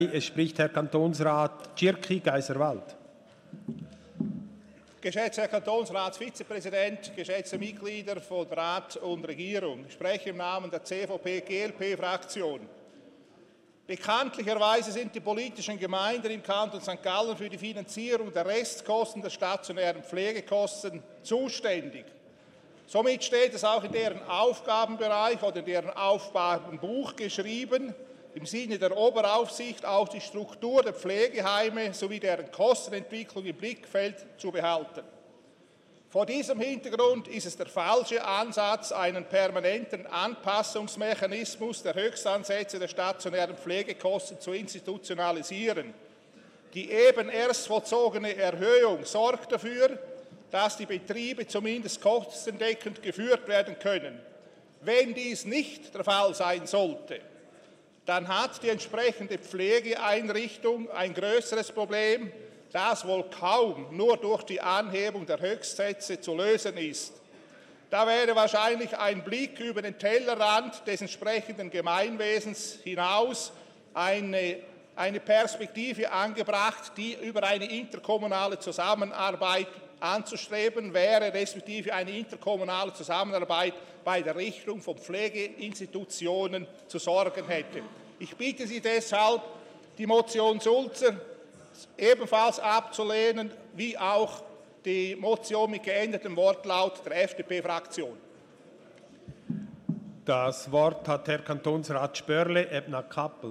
Session des Kantonsrates vom 23. und 24. April 2019